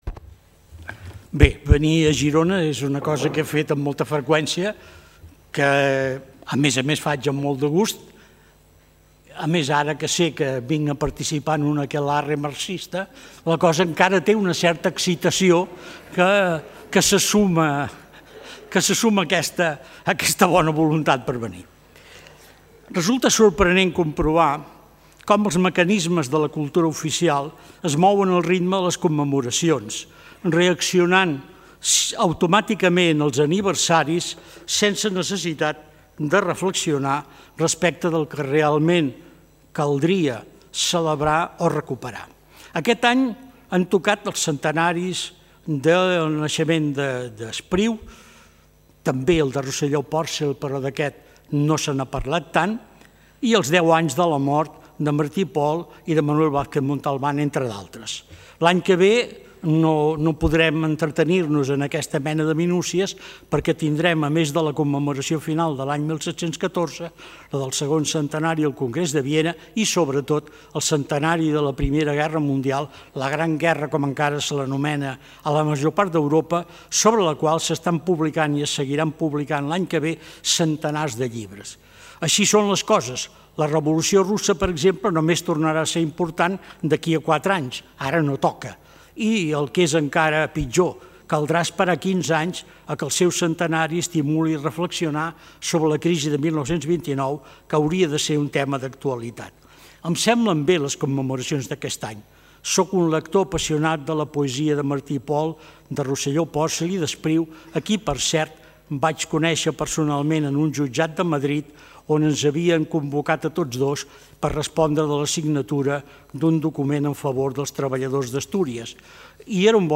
Conferència a càrrec del Dr. Josep Fontana sobre el Pierre Vilar inèdit